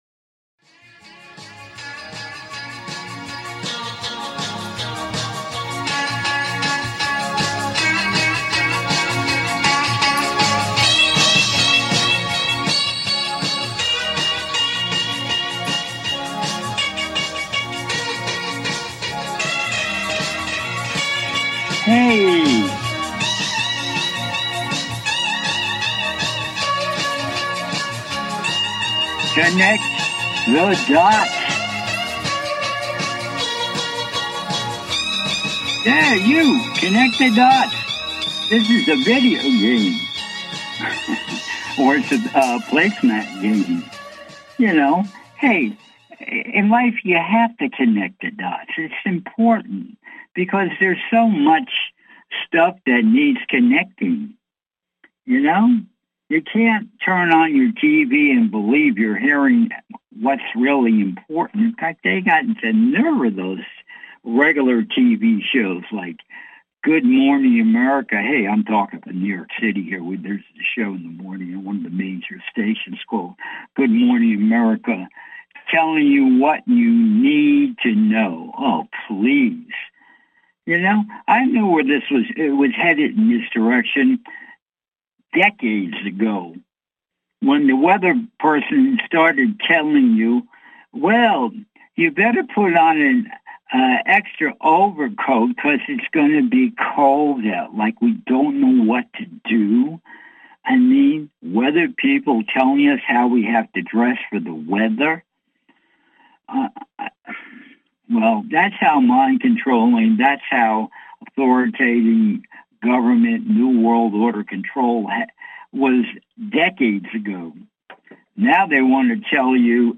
"CONNECT THE DOTS" is a call in radio talk show, where I share my knowledge of the metaphysical, plus ongoing conspiracies, plus the evolution of planet earth - spiritual info - et involvement - politics - crystals - etc.